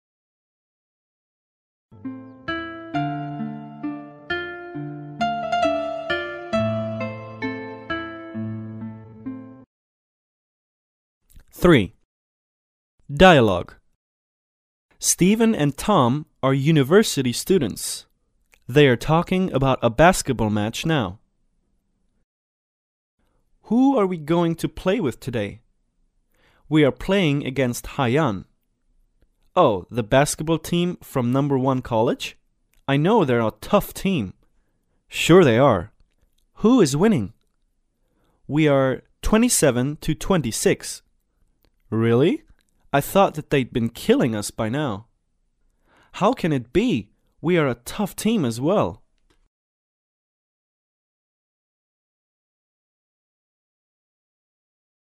对话
Steven and Tom are university students. They are talking about a basketball match now.